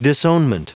Prononciation du mot disownment en anglais (fichier audio)
Prononciation du mot : disownment